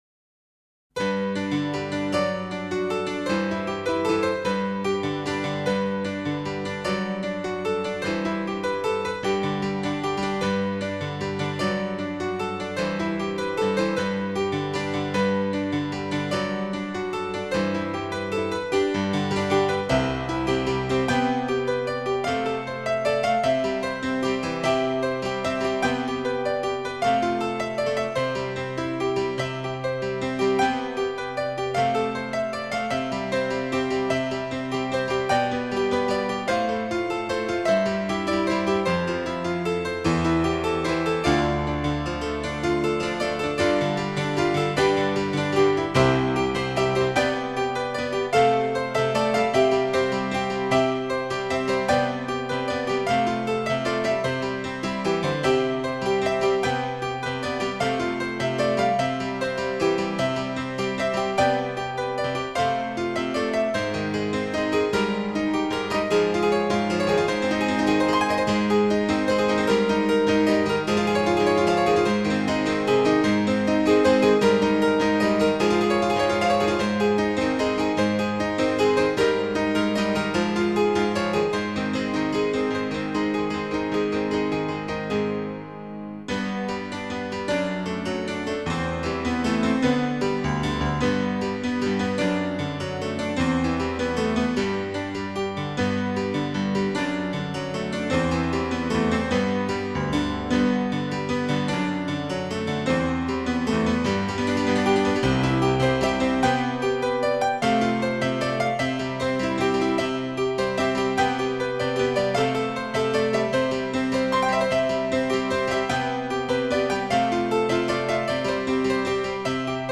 僅低音質壓縮 , 供此線上試聽